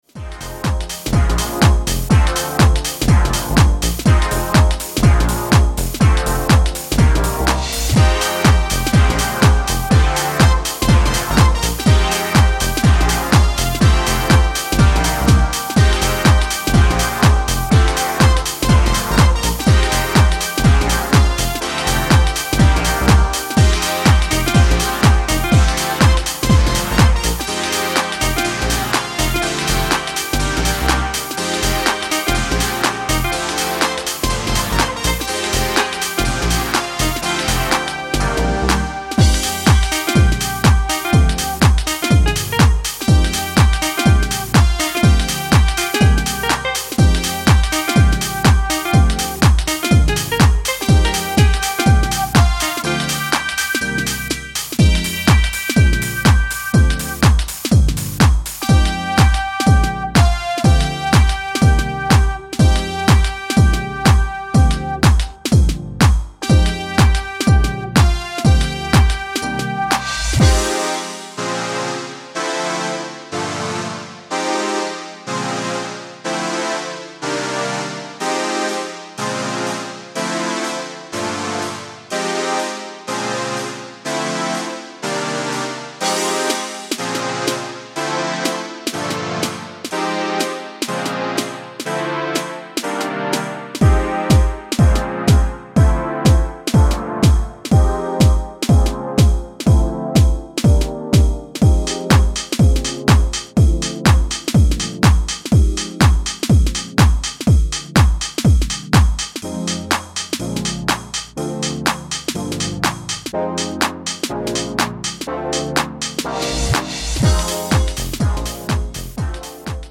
irresistible Windy City sound
four stomping house bangers